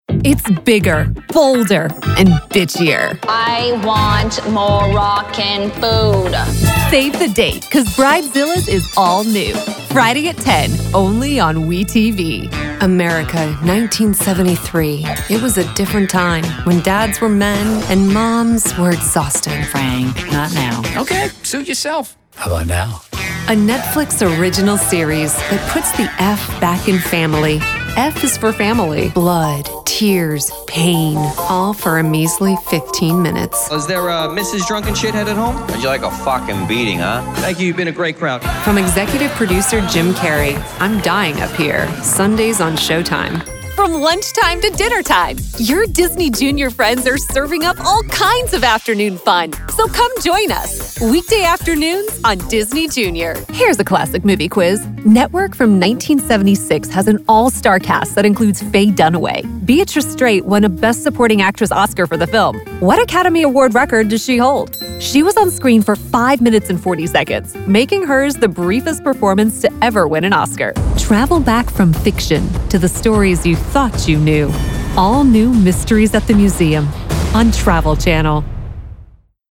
Promo Demo